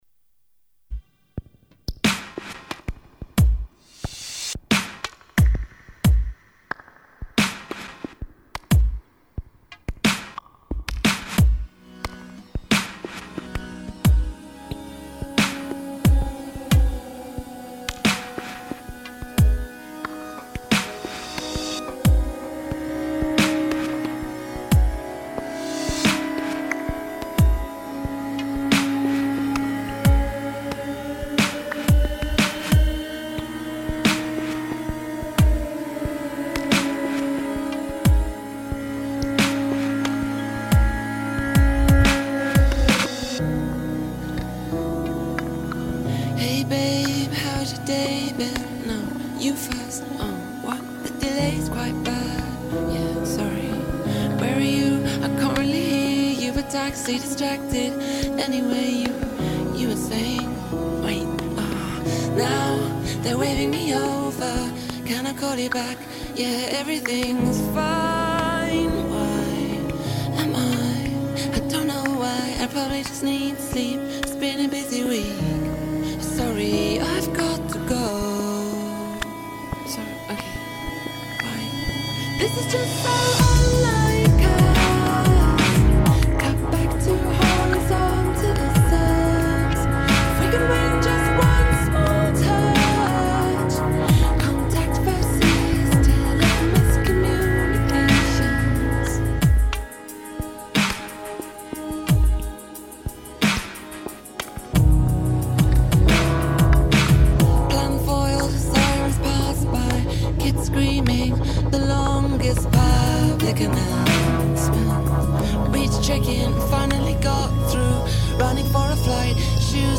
A very pretty edit